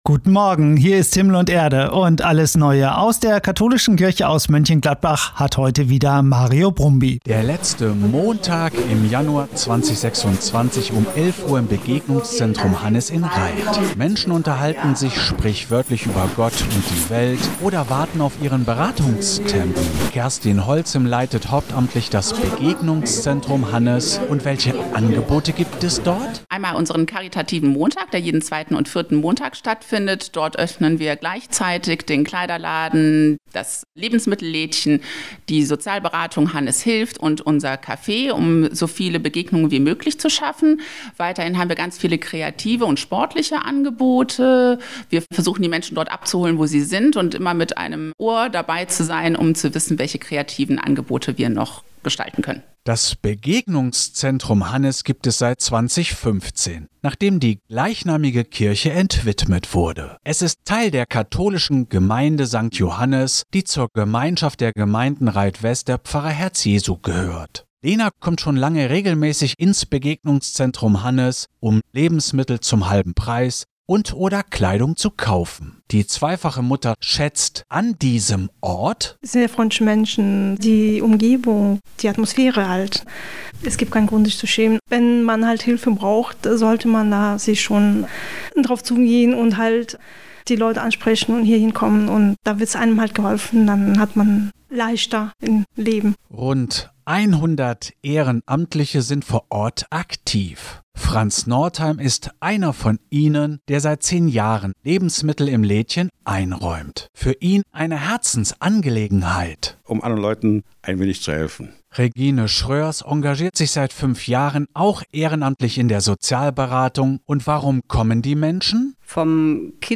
Am vergangenen Sonntag wurde unser karitativer Montag auf besondere Weise gewürdigt – im Radio.